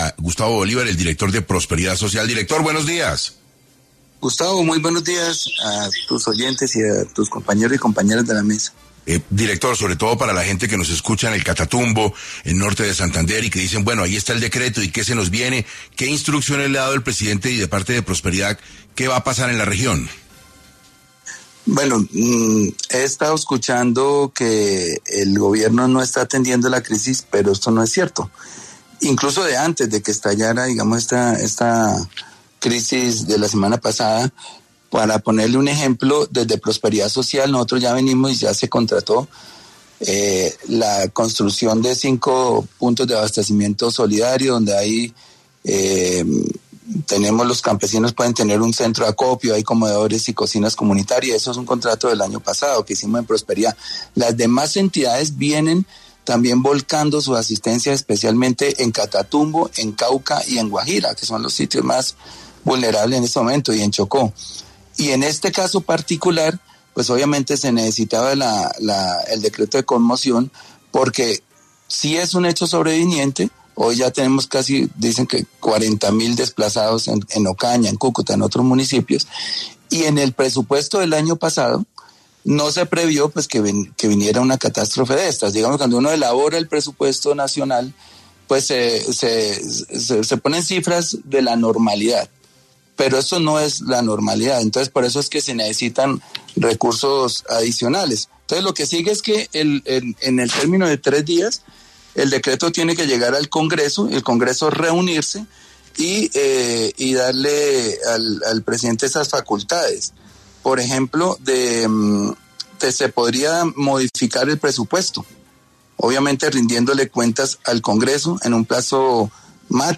Gustavo Bolívar, director de Prosperidad Social, habló en 6AM sobre como se determinarán y ejecutarán los recursos para la conmoción interior